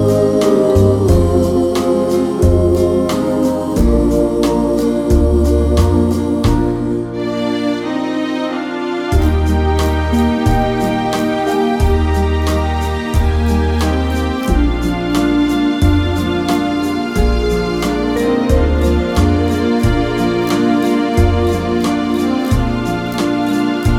no Backing Vocals Crooners 3:37 Buy £1.50